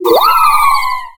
Cri de Phyllali dans Pokémon X et Y.